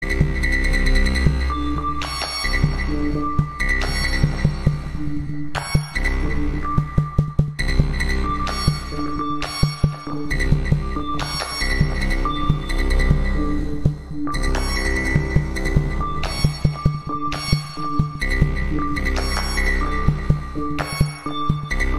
Звук COVID-19 в цифровой обработке